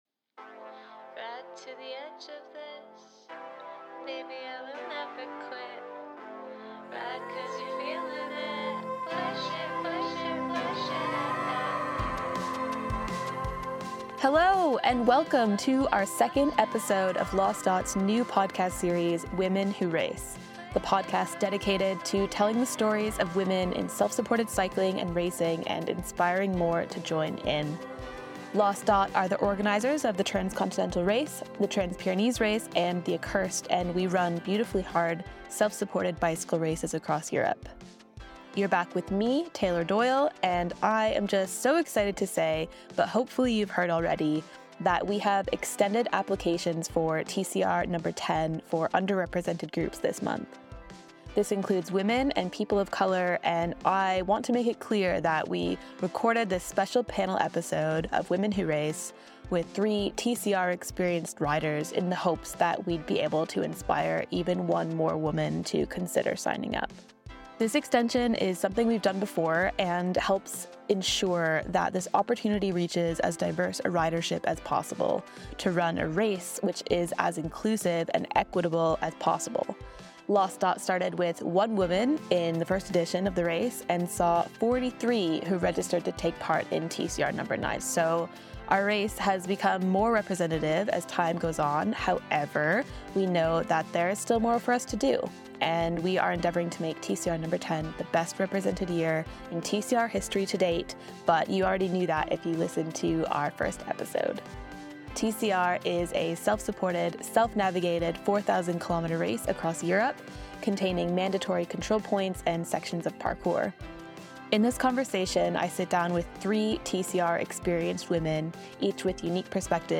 Listen to part one of their dynamic and insightful conversation, diving deep into the emotional and physical landscape of pre, post, and on-race realities.